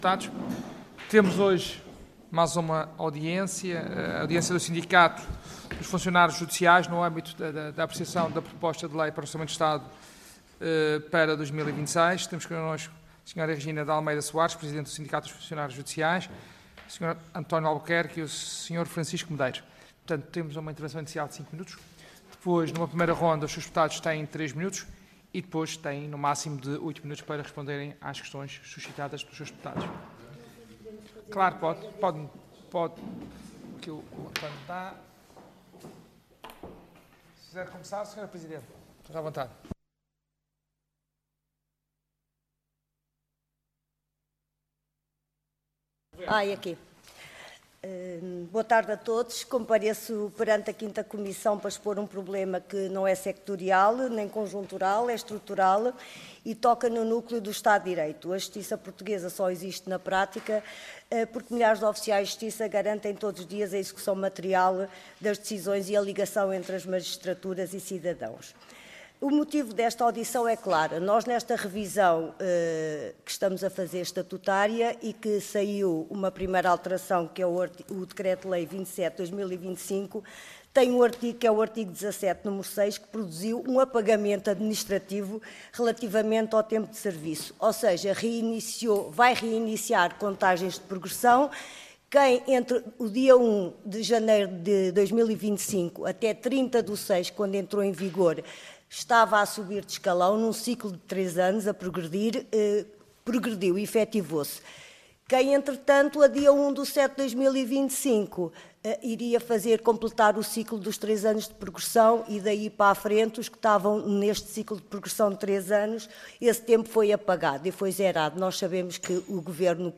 Comissão de Orçamento, Finanças e Administração Pública Audiência Parlamentar Nº 9-COFAP-XVII Assunto Audiência do Sindicato dos Funcionários Judiciais, no âmbito da apreciação, na especialidade da Proposta de Lei n.º 37/XVII/1.ª (GOV) – Orçamento do Estado para 2026.